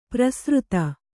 ♪ prasřta